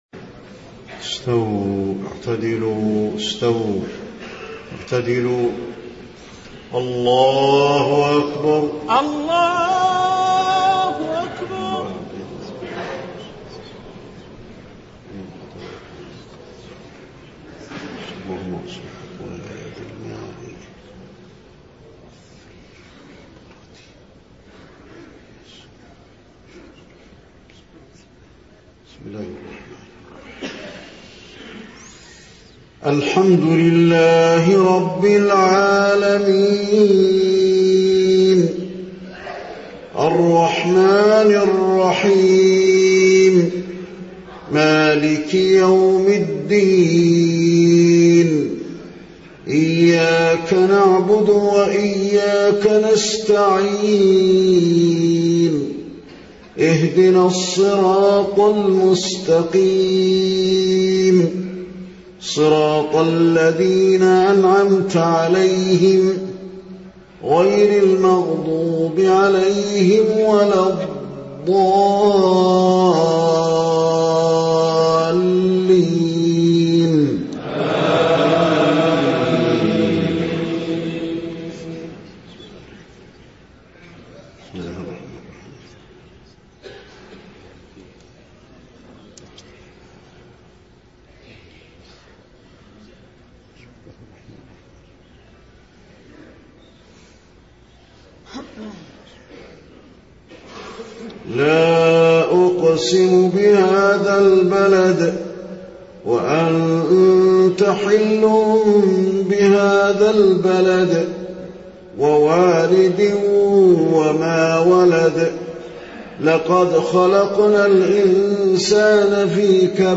صلاة العشاء 20 محرم 1430هـ سورتي البلد و الشمس > 1430 🕌 > الفروض - تلاوات الحرمين